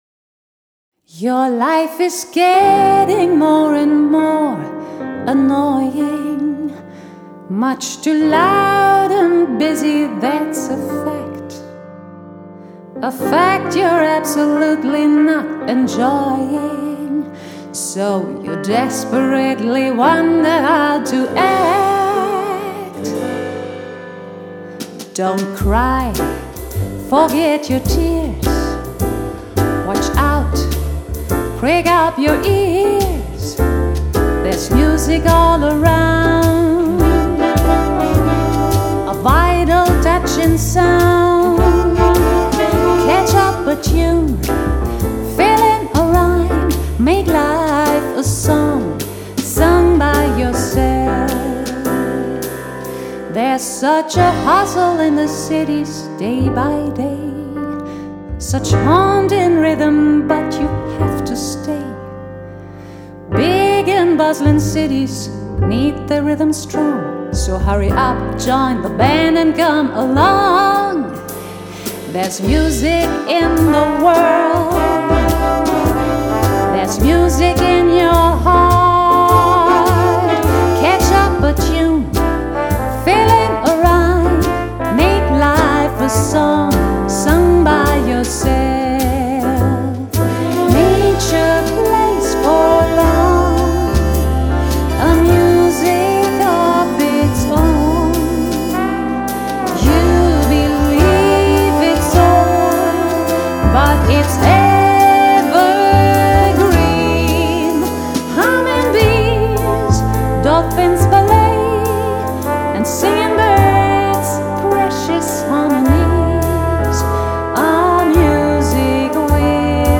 Sophisticated pop and jazz songs
A soulful, stylish and timeless CD.